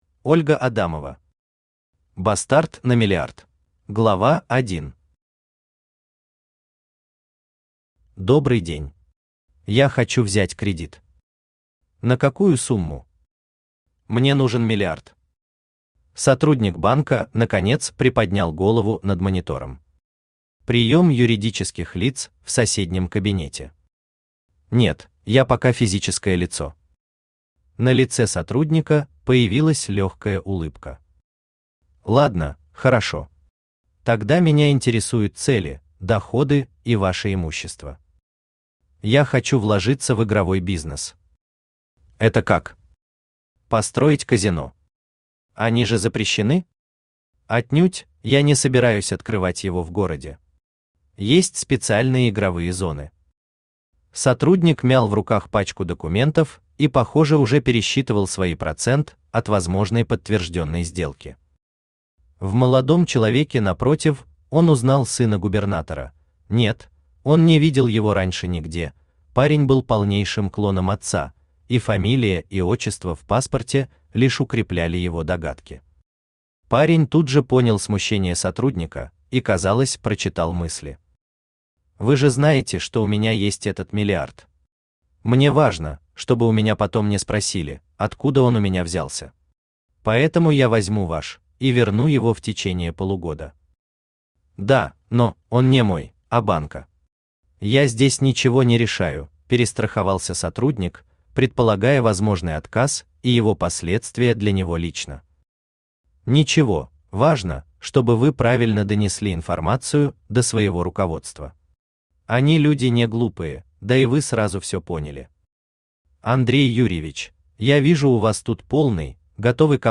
Аудиокнига Бастард на Миллиард | Библиотека аудиокниг